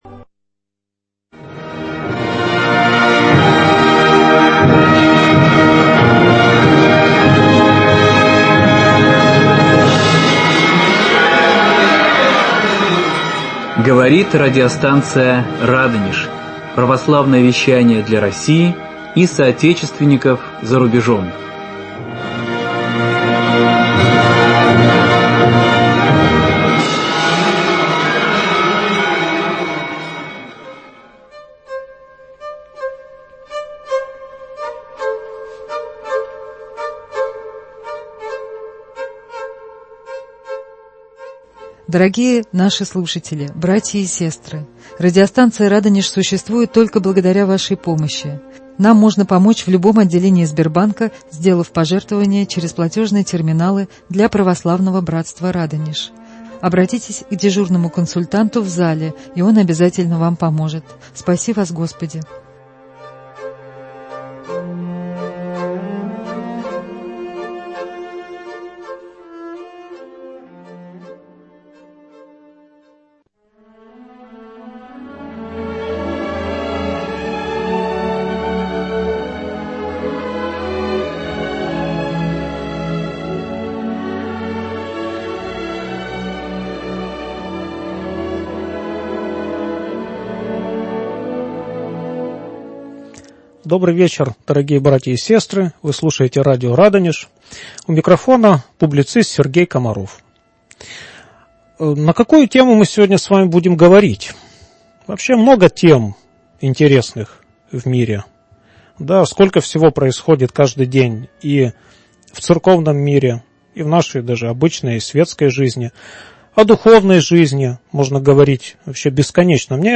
В прямом эфире радиостанции "Радонеж" со слушателями общается православный публицист и катехизатор